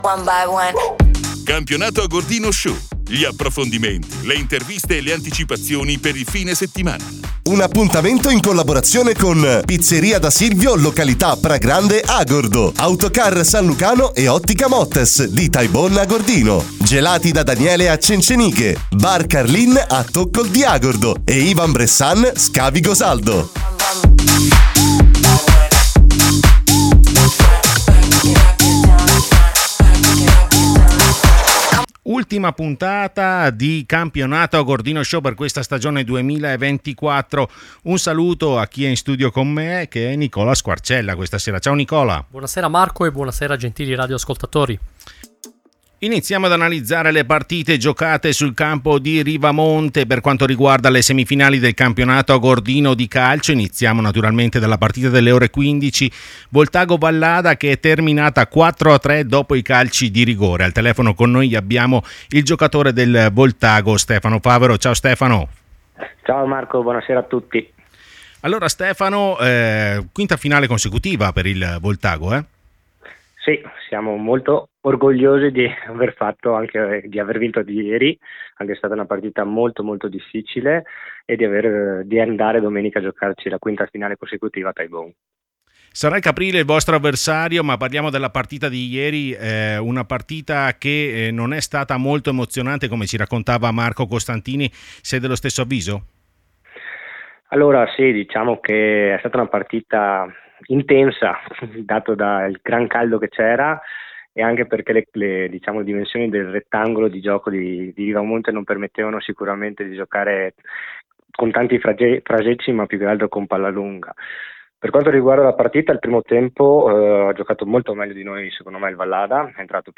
REDAZIONE Ultimo appuntamento con Campionato Agordino Show per analizzare le semifinali del Memorial Attilio Moretti. In studio
Ospiti al telefono